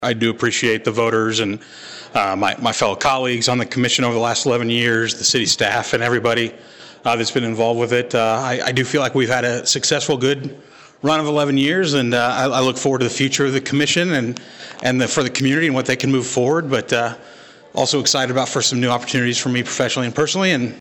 Colleagues, friends and community members all convened inside White Auditorium’s Little Theatre Wednesday to bid farewell to a longtime civil servant.